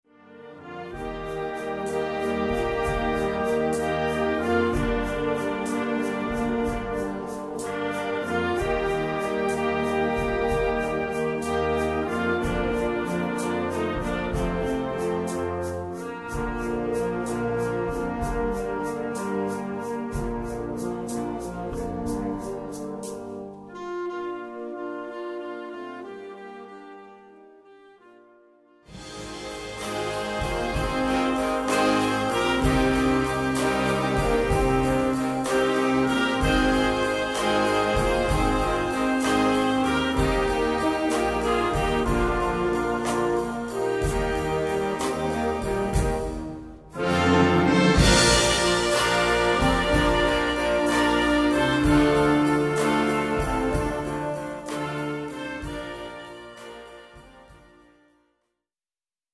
Gattung: Pop-Ballade
A4 Besetzung: Blasorchester PDF